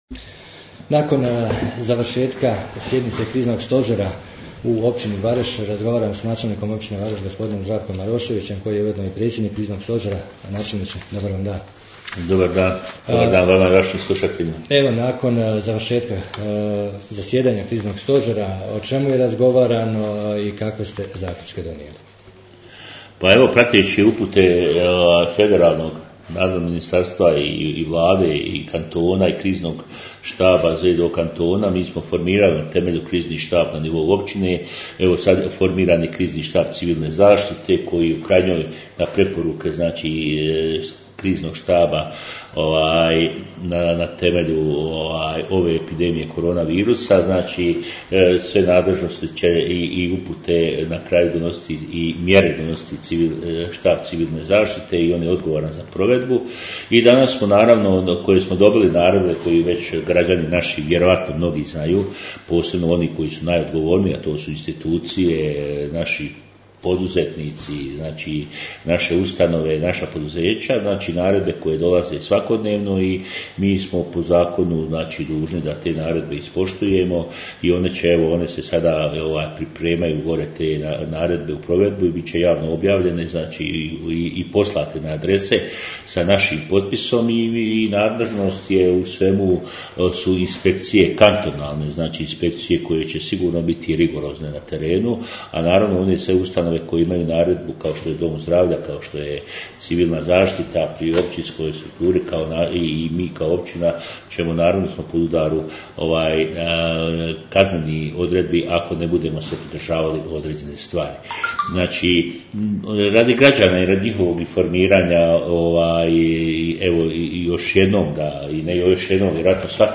Načelnik Zdravko Marošević - razgovor nakon sastanka Kriznog stožera